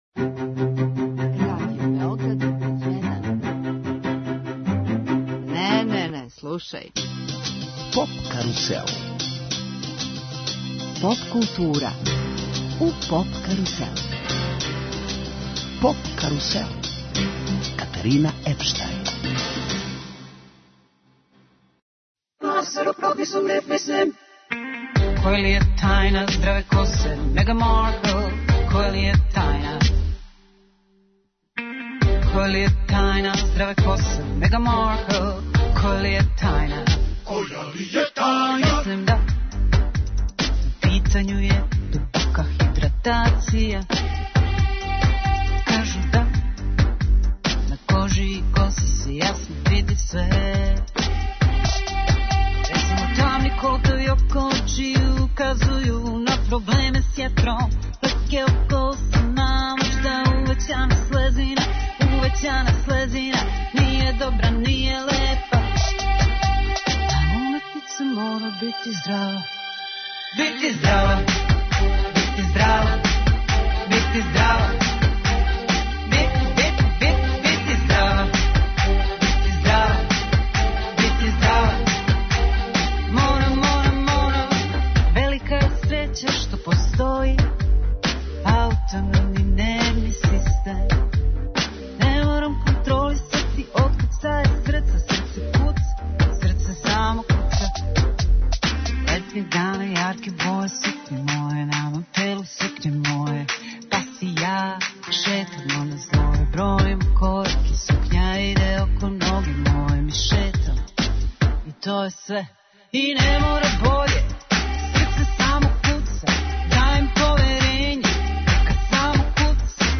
Емисија 'Поп карусел' је посвећена Евросонгу а музиком подсећамо на победнике, највеће хитове и домаће представнике, највећег европског музичког такмичења.